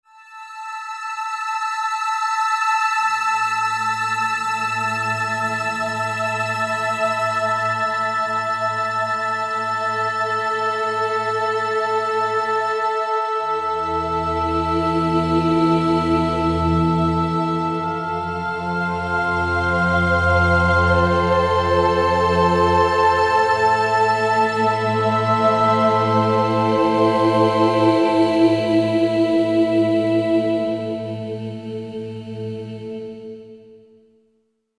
A large collection of mystical Choir and Vocal sounds that will take you on a magical journey.